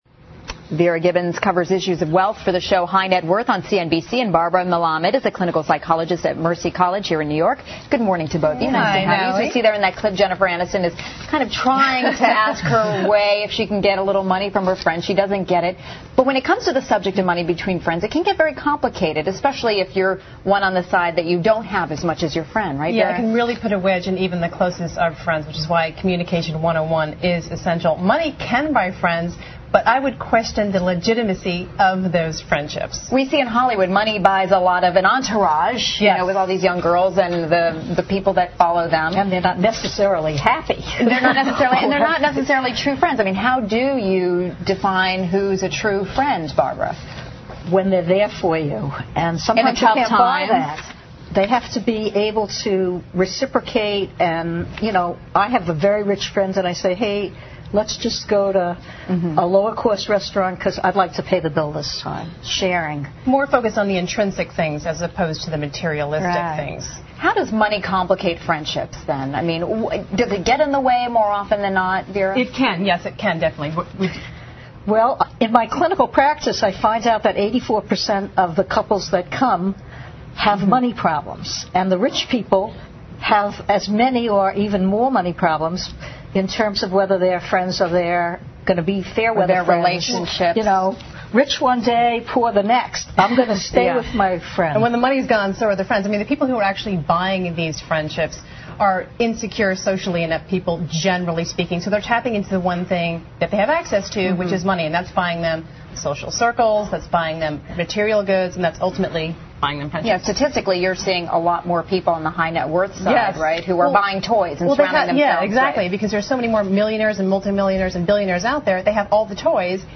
访谈录 Interview 2007-08-31&09-02, 钱可以买到友情吗？ 听力文件下载—在线英语听力室